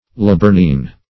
Search Result for " laburnine" : The Collaborative International Dictionary of English v.0.48: Laburnine \La*bur`nine\, n. (Chem.)